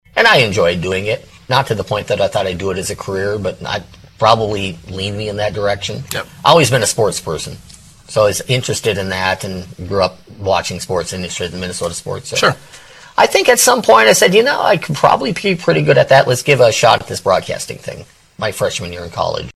Pirates Digital Media provided the audio for the interview